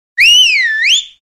Whistle4.wav